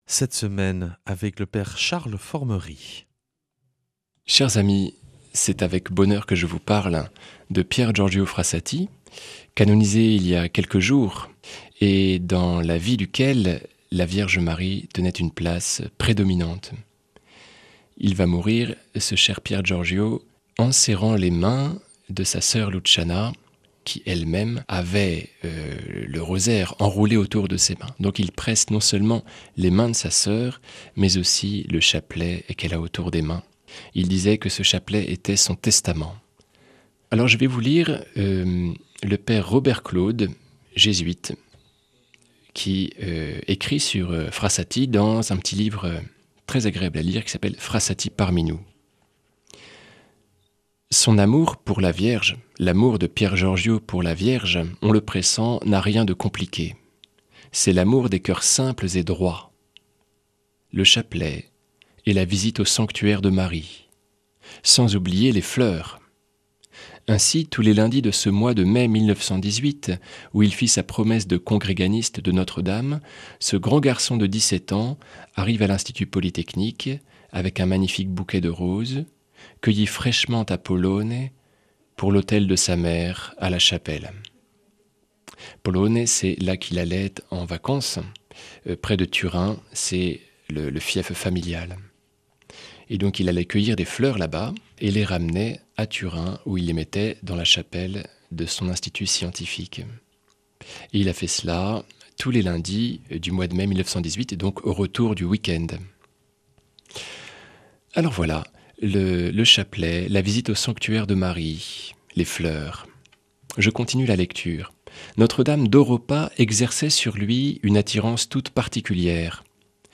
lundi 29 septembre 2025 Enseignement Marial Durée 10 min